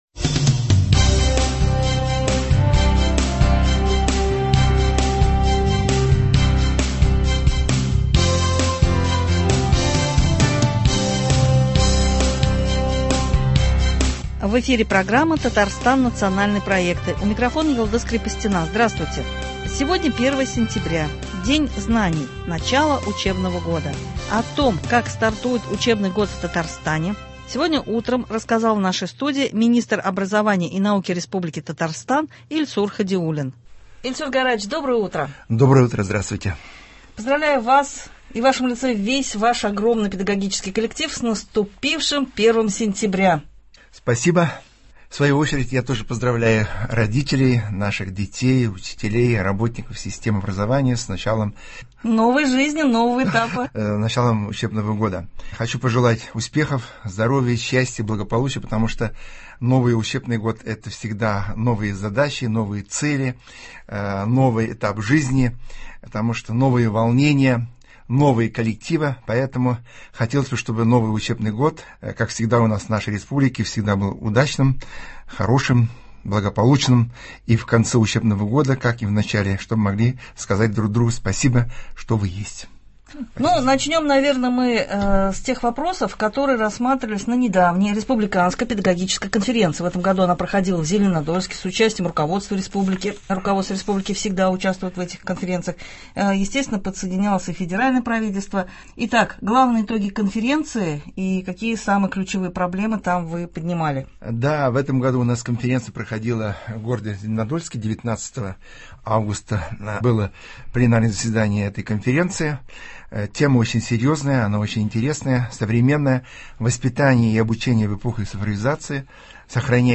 Сегодня — День знаний, в студии министр образования и науки Татарстана Ильсур Хадиуллин.